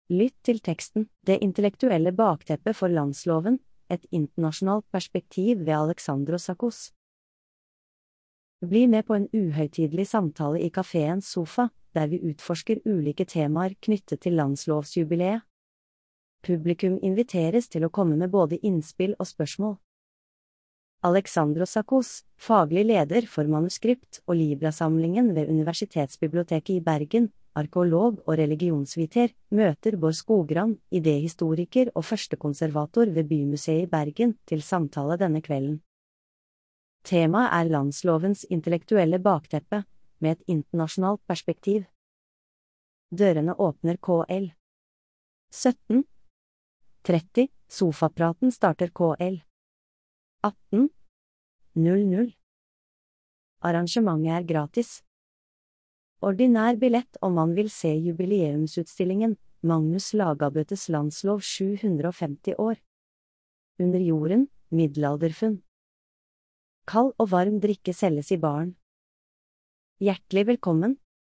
Sofaprat på Bryggens Museum. Denne gangen tar vi for oss den unike Guddaldrakten - et nesten tusen år gammelt myrfunn som er et av de ytterst få relativt intakte klesplaggene vi har fra norsk middelalder.